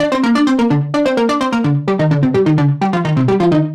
Index of /musicradar/french-house-chillout-samples/128bpm/Instruments
FHC_Arp A_128-C.wav